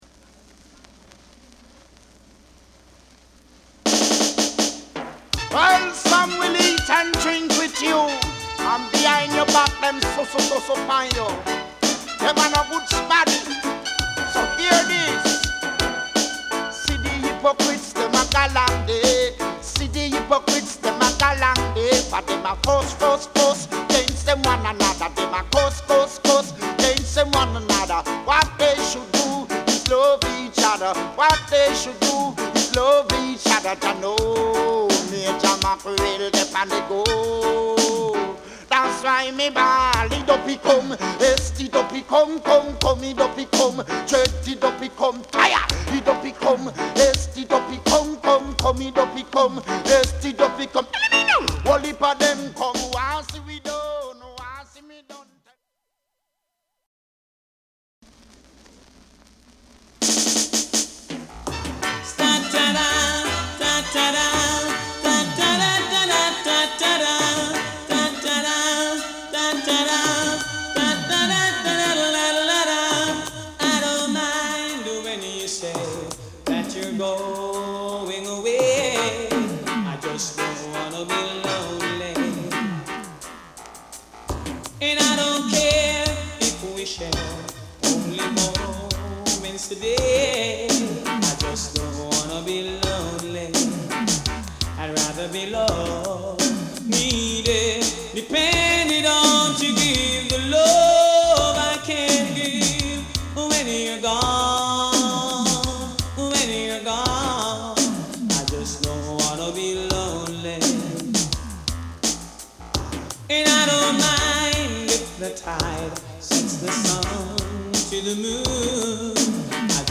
プレス・ノイズ有り（JA盤、Reggaeのプロダクション特性とご理解お願い致します）。